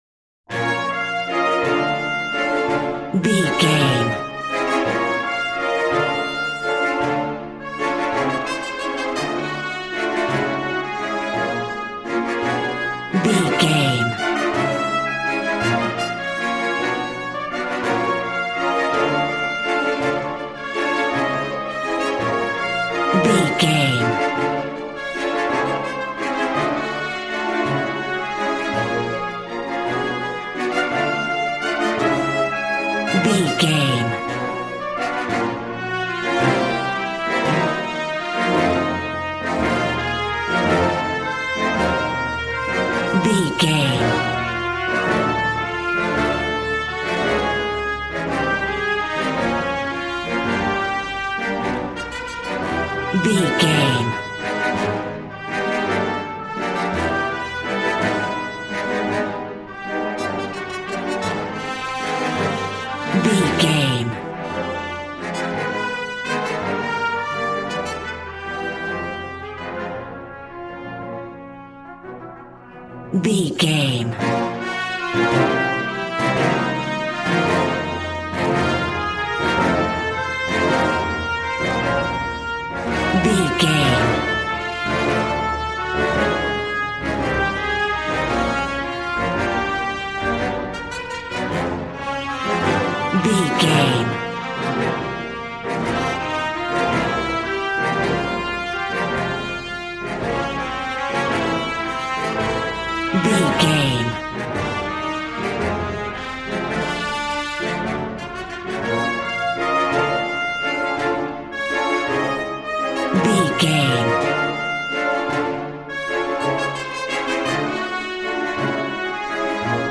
Ionian/Major
dramatic
epic
percussion
violin
cello